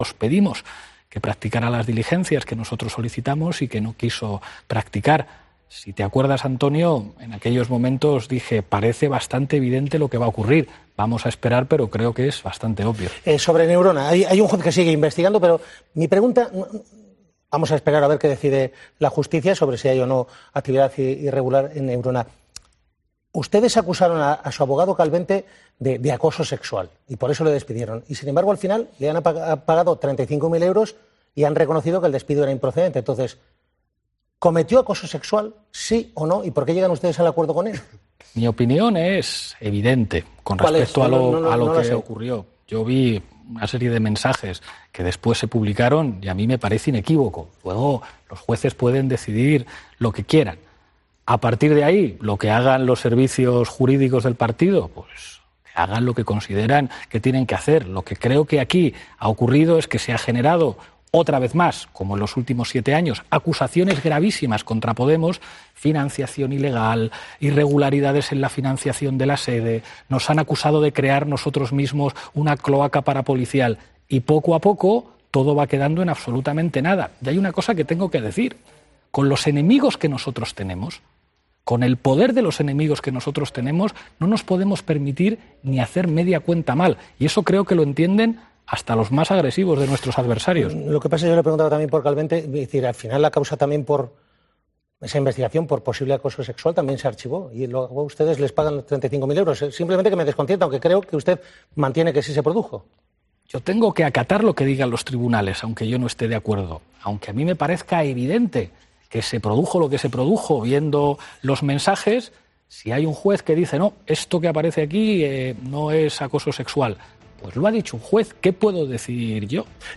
El vicepresidente del Gobierno ha asistido al plató de laSexta para analizar junto al presentador los hechos más relevantes de la actualidad política
Este lunes, 'Al Rojo Vivo' ha arrancado con una entrevista a Pablo Iglesias.